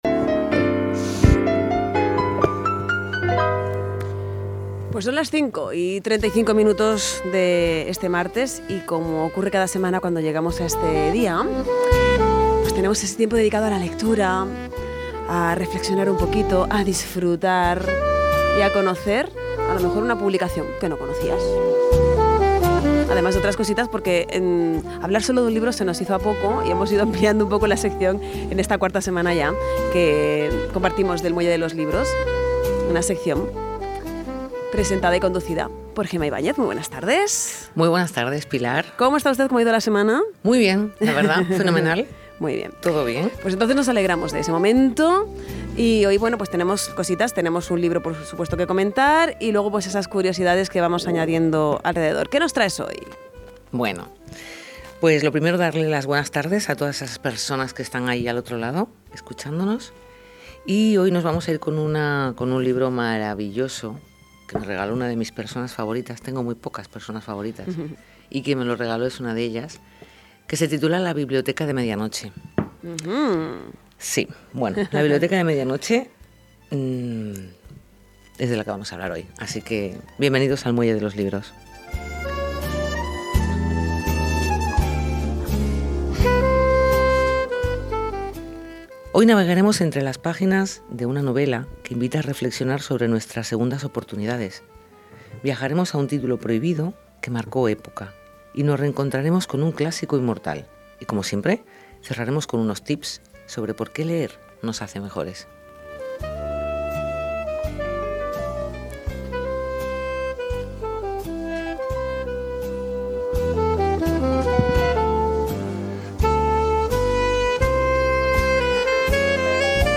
Reflexión, tips, libros prohibidos… todo unido con una selección musical muy especial, hacen que este espacio sea tremendamente atractivo para los oyentes de Radio Insular Fuerteventura .